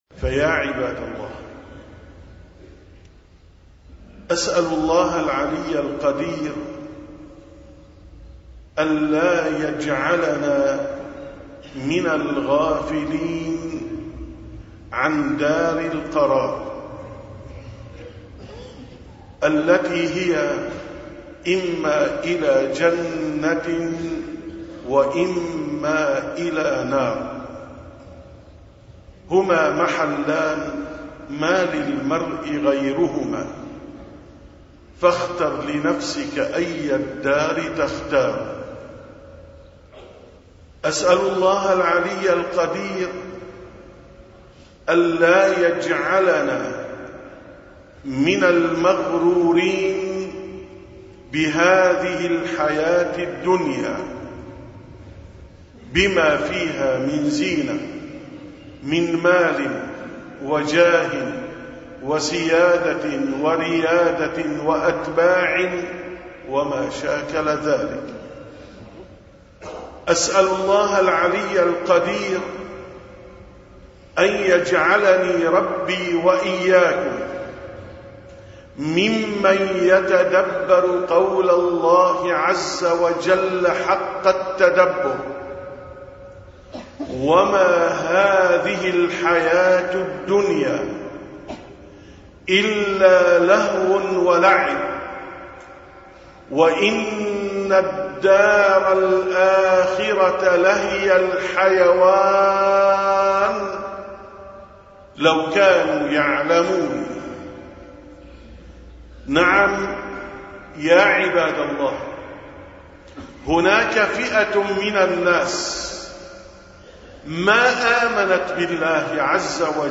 867ـ خطبة الجمعة: يا عبد الله لا تكن من الغافلين